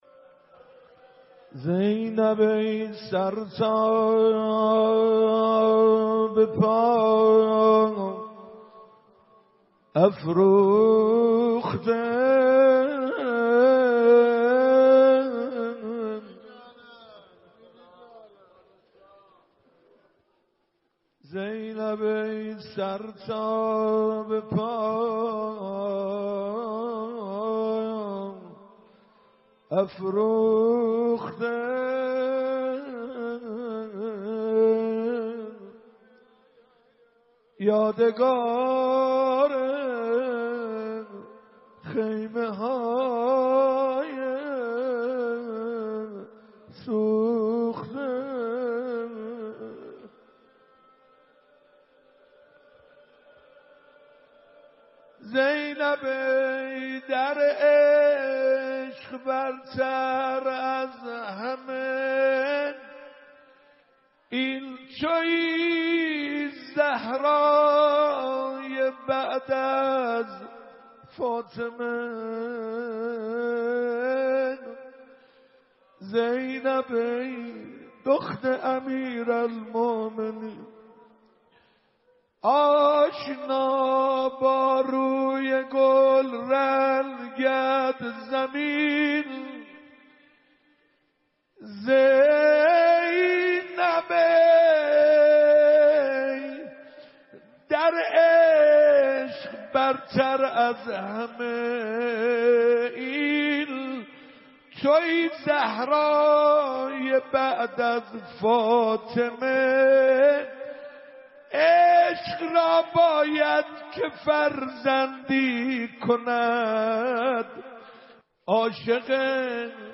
شب هشتم ماه مبارک رمضان 96 - مسجد ارک - مناجات ماه رمضان و روضه